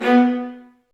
Index of /90_sSampleCDs/Roland L-CD702/VOL-1/STR_Vlas Marcato/STR_Vas2 Marcato